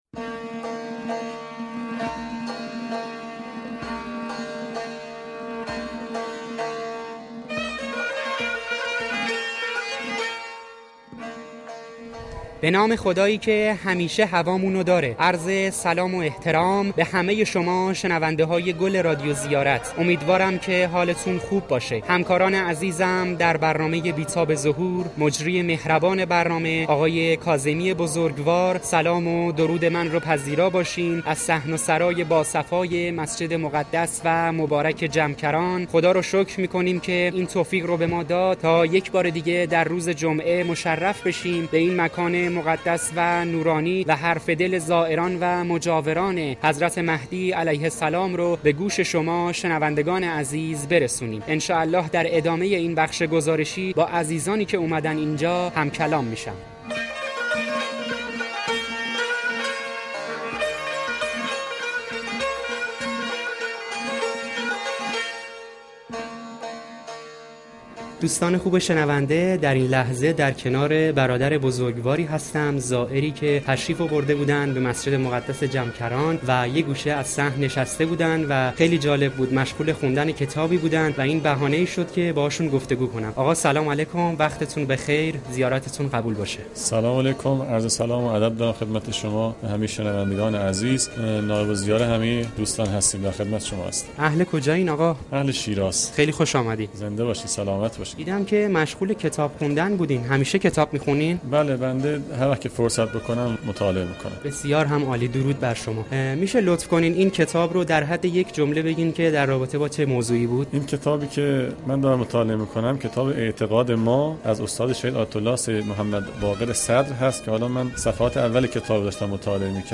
پادکست گزارشی از مسجد مقدس جمکران و گفت و گو با زائران و مجاوران درباره مهدویت و انتظار